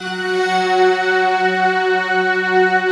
SI1 CHIME0AL.wav